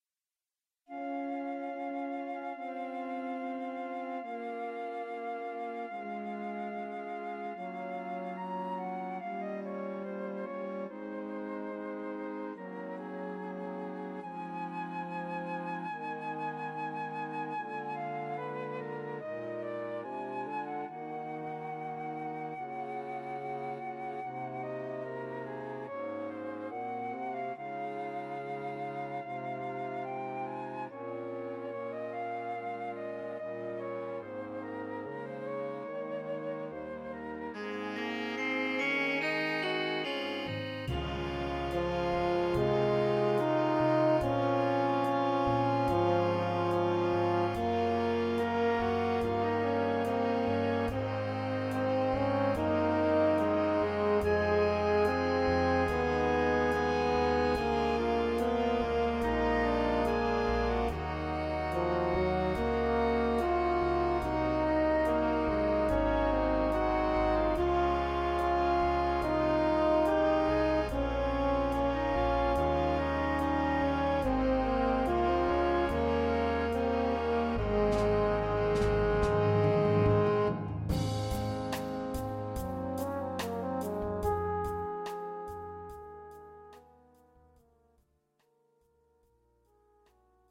Gattung: Ballade
Besetzung: Blasorchester